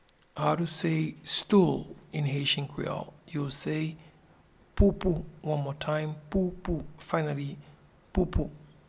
Pronunciation and Transcript:
Stool-in-Haitian-Creole-Poupou.mp3